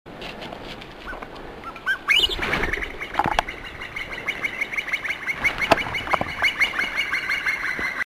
The Himalayan Snowcock can be noisy birds.
The snowcock I have seen will make this alarm call most if not the whole time it is flying. Below is the recording of 2 Ruby Mountain Himalayan Snowcock as they glided past.
Snowcock Alarm Call
snowcockcall1.mp3